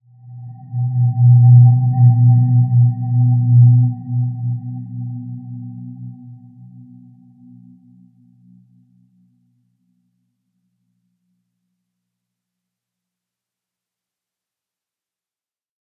Dreamy-Fifths-B2-p.wav